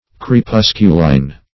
Crepusculine \Cre*pus"cu*line\ (-l[i^]n)